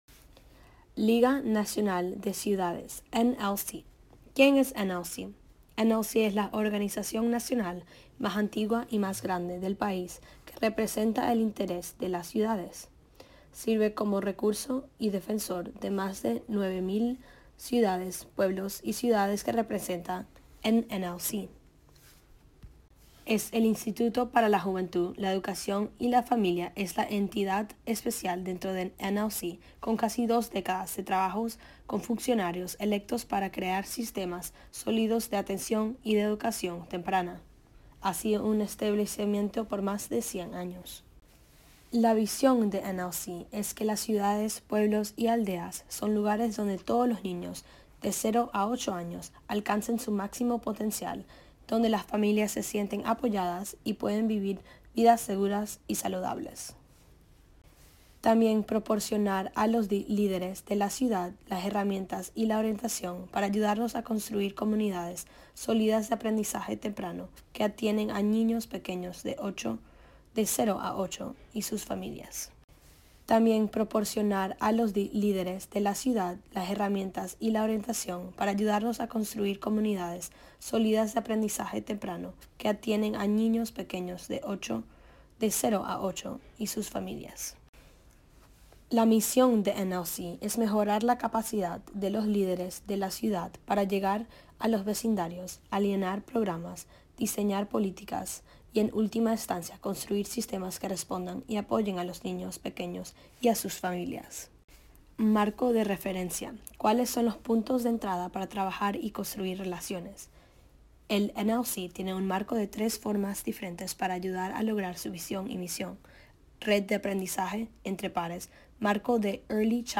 Spanish Audio Summary